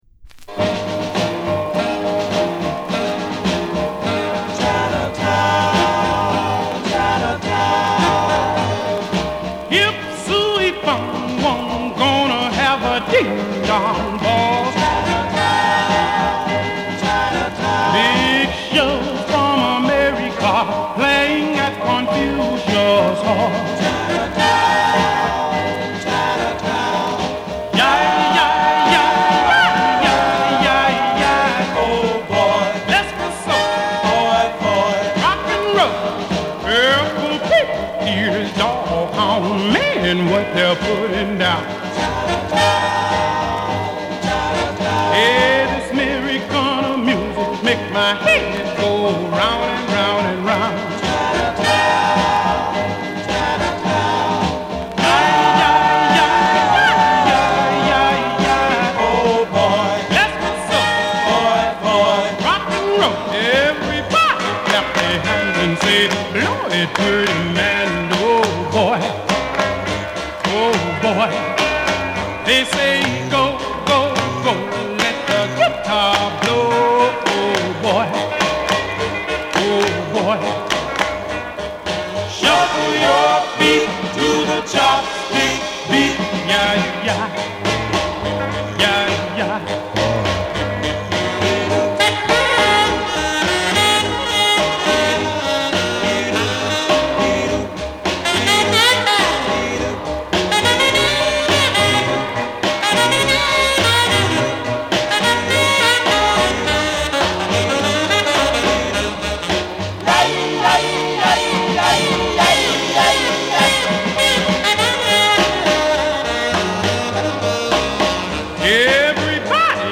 A面はオリエンタル・メロディを配したミッドスロー・グループR&Bノヴェルティ。
[Comped] [Tittyshaker] [Exotica] [NEW]
バックグラウンドノイズはいります。